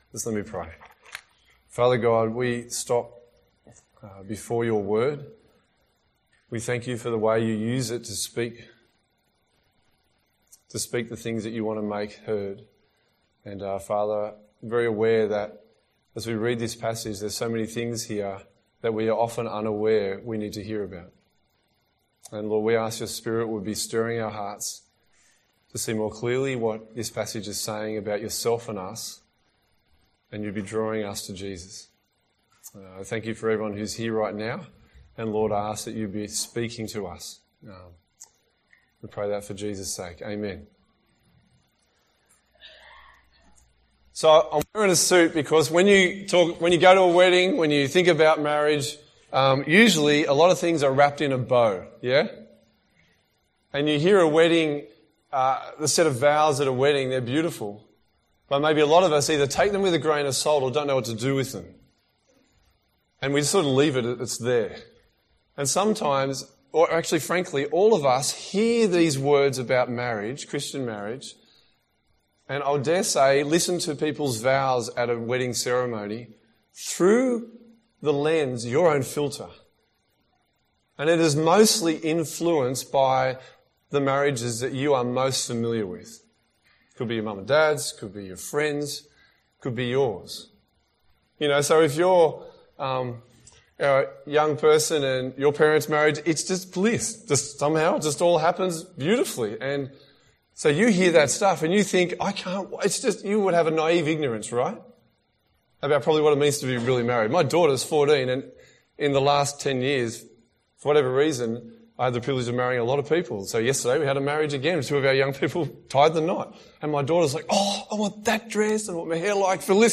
A message from the series "A Life That Stands (PM)."